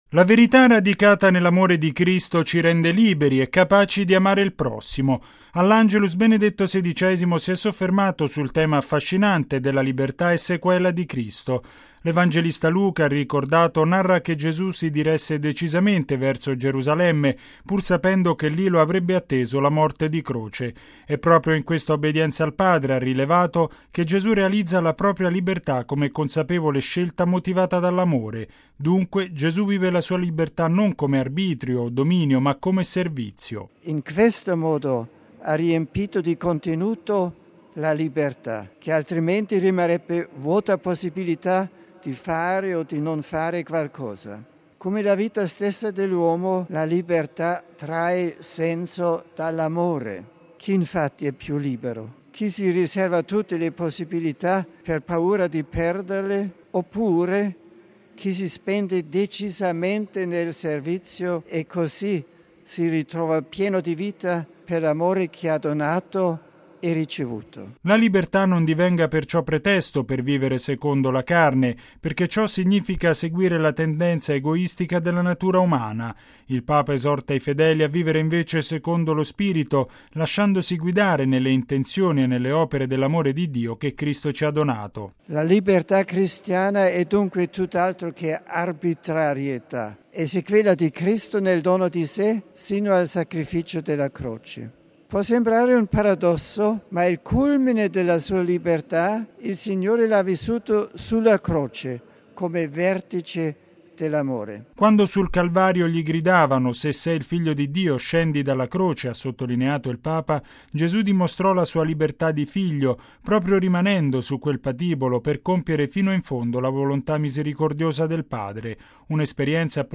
◊   Chi appartiene alla verità sarà sempre libero e capace di mettersi al servizio dei fratelli: è quanto affermato dal Papa all’Angelus, in Piazza San Pietro.